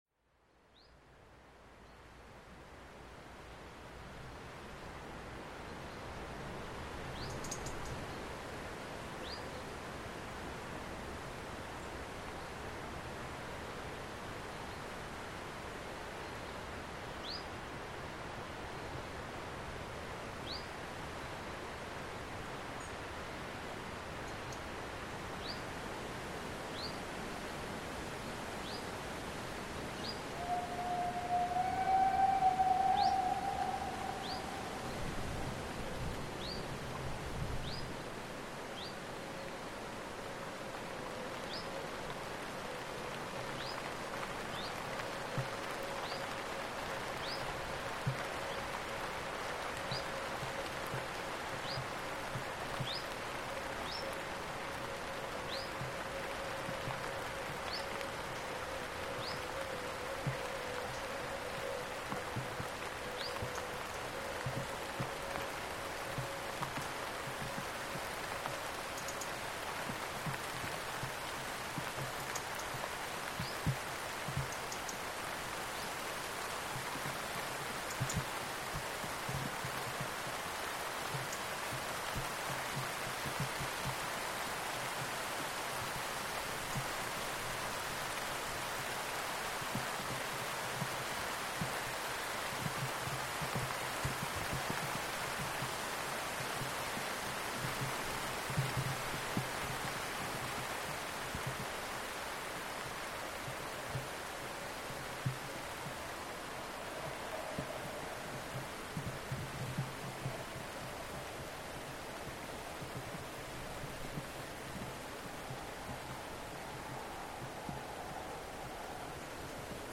Anlässlich des Bahnhofsjubiläums (125 Jahre schon!) in Ochsenhausen kam sie vor einem Sonderzug zusätzlich zu den „Regelzügen“ am 14.September des Jahres zum Einsatz. Der Zug war zwar mit nur 4 Wagen für die Lok eigentlich etwas zu kurz und hatte damit geringe Last, aber... sie wurde trotzdem heftig gut gefahren, was dann auch ungeahnte Geschwindigkeit und ungewohnten, etwas „verschwommenden“ aber trotzdem sehr schön lauten Sound der Mallet zur Folge hatte:
99 633 Tv vor 4-Wagen-Sonderzug Zug Ochsenhausen→Warthausen, hinter Wennedach etwa 500m vor dem Scheitelpunkt zwischen den Feldern, um 9:47h am 14.09.2025.   Hier anhören: